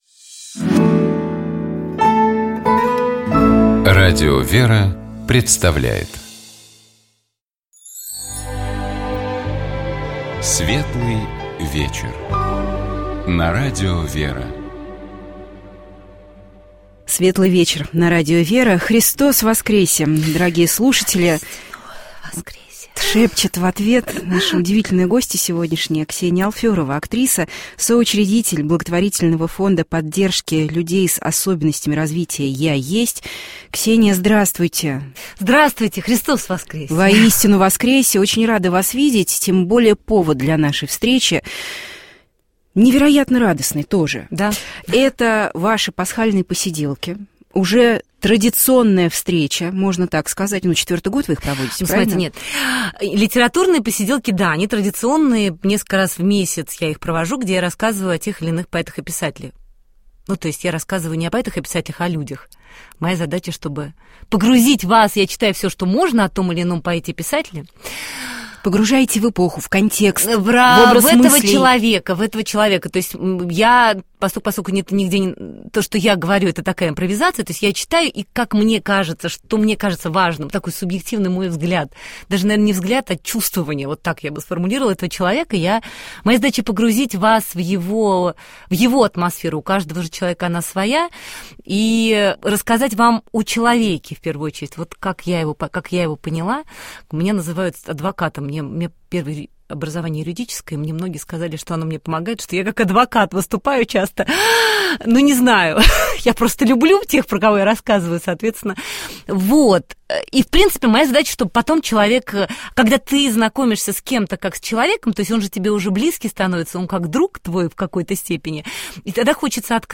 У нас в гостях была актриса, соучредитель фонда поддержки людей с особенностями развития «Я есть» Ксения Алферова.